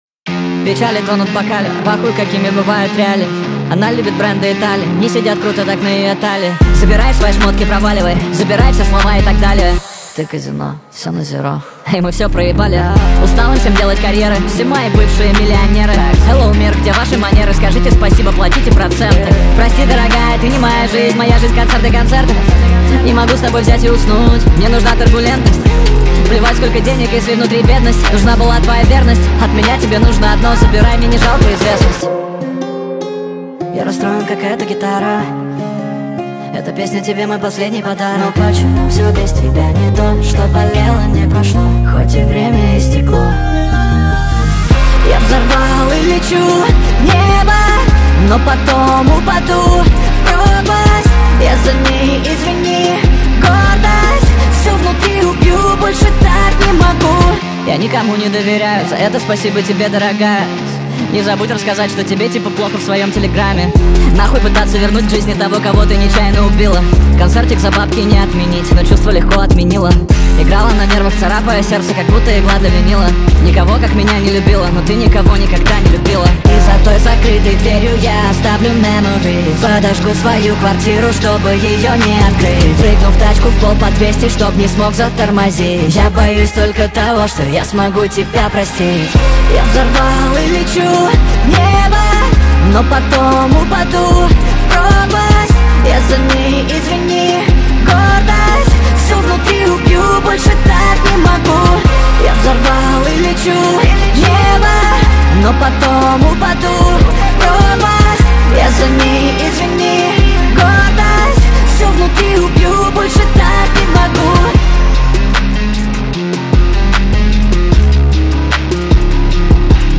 speed up tiktok remix